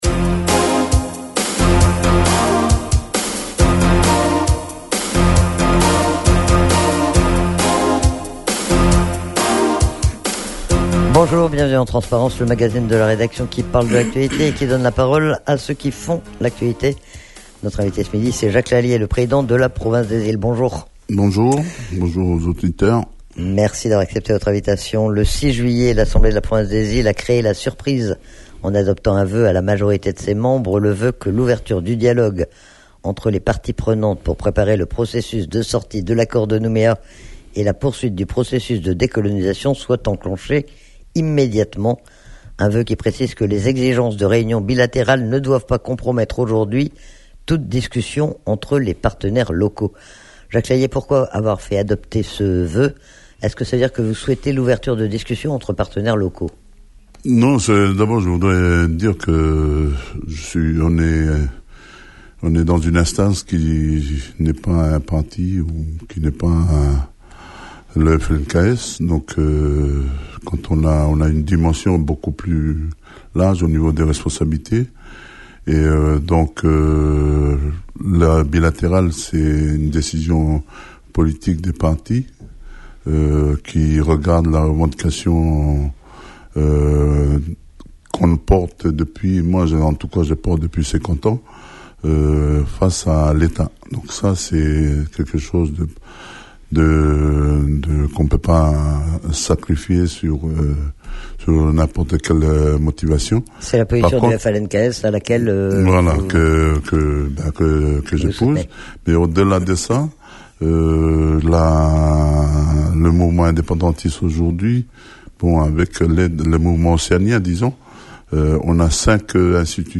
Il est interrogé sur l'actualité politique et notamment sur l'avenir institutionnel ou sur le vœu adopté récemment par l'assemblée de la province des Iles.